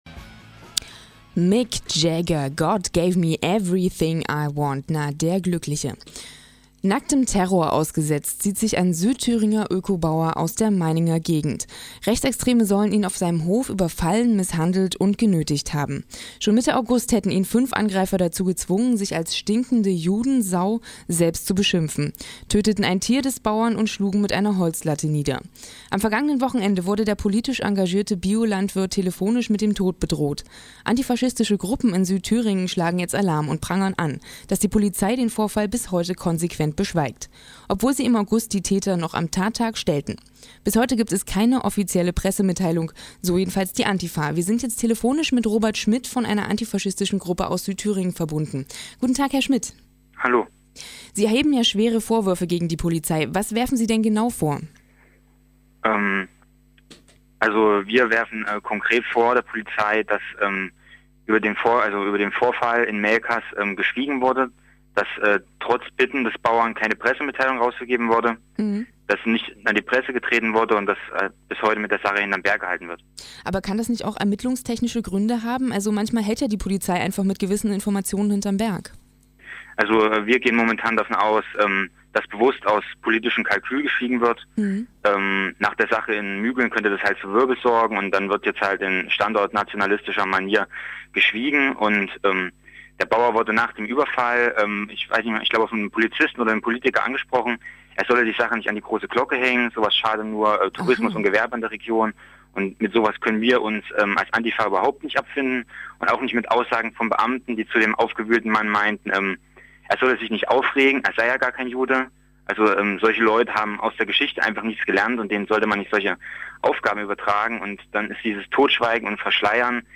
Radio-Interview mit Radio Lotte Weimar